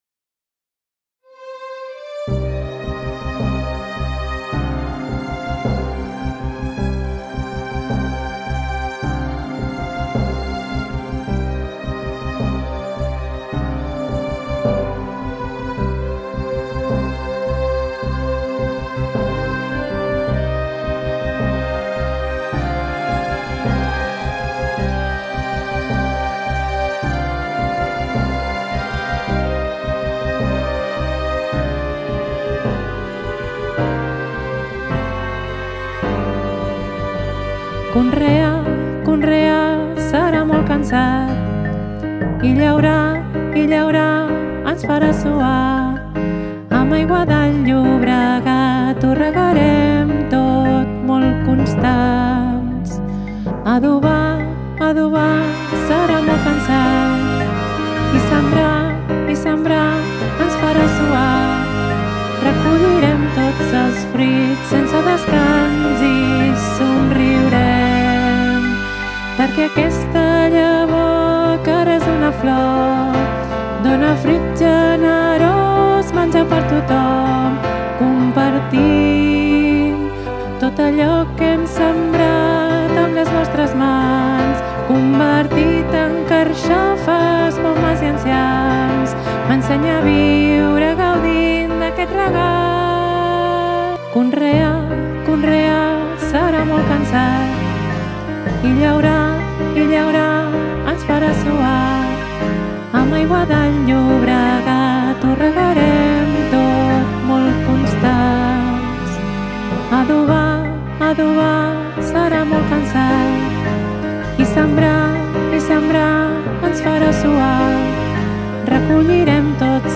El-camp-veu.mp3